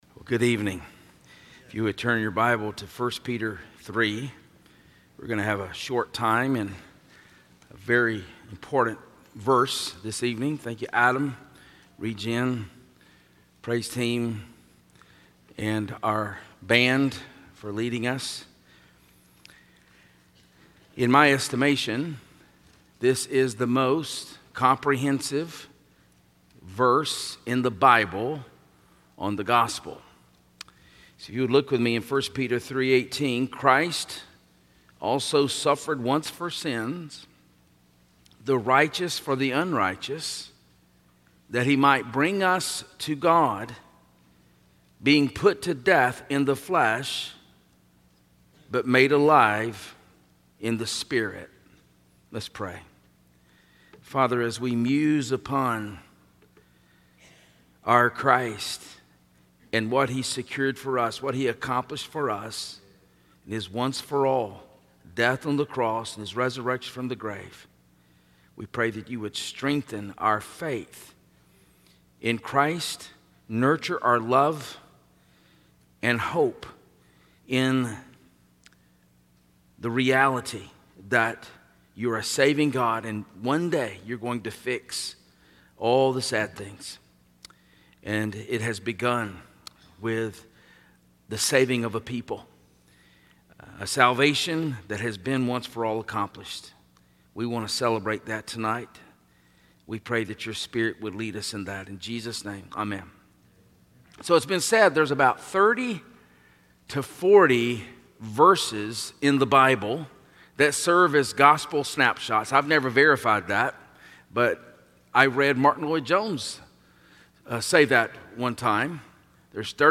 Stand Alone Sermons
Service Type: Sunday Evening